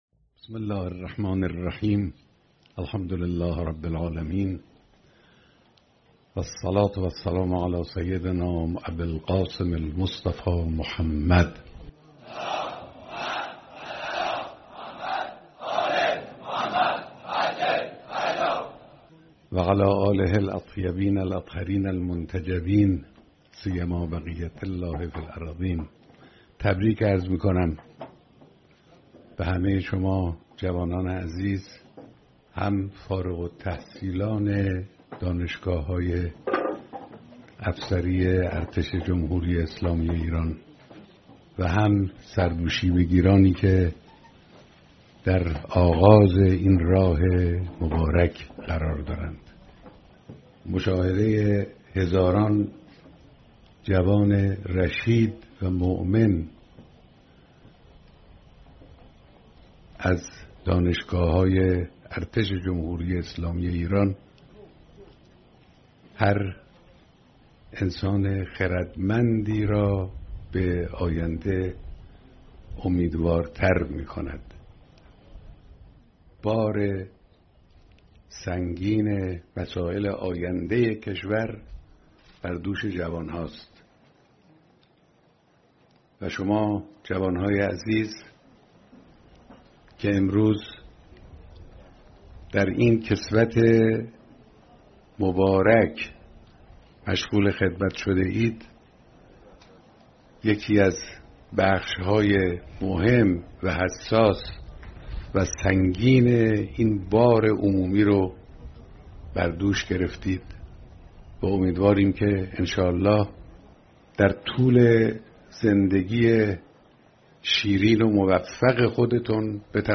حضور و سخنرانی در دانشگاه علوم دریایی امام خمینی(ره) نوشهر
بیانات فرمانده کل قوا در دانشگاه علوم دریایی امام خمینی(ره) نوشهر